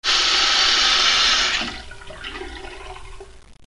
Scarico del water
Suono dello scarico del WC che manda gił l'acqua in pressione..rumore del pulsante e gorgoglio finale. Clunc, fluush..gorgle gorgle..
Effetto sonoro - Scarico del water